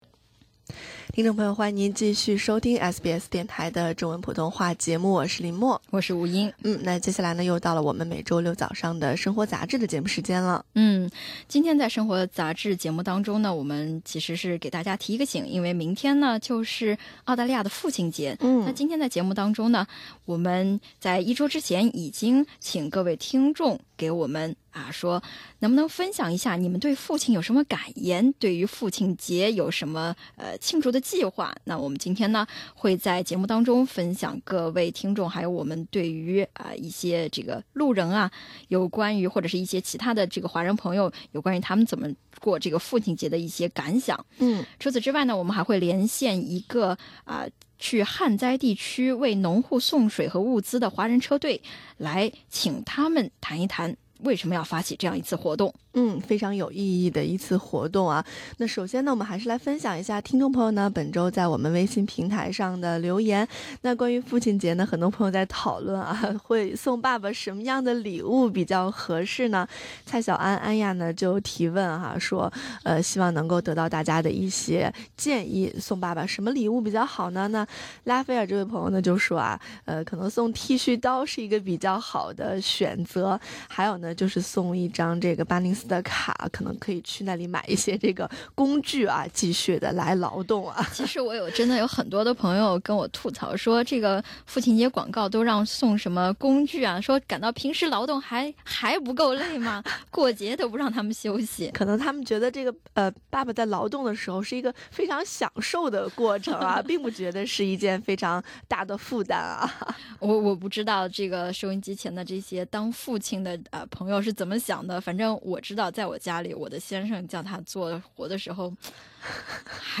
父亲节将至，华人小朋友、当地留学生、在职工作者、奶爸奶妈们以及我们的微信听友跟大家分享自己对父亲节的感悟，如何庆祝这个父亲节以及和父亲相处的点滴。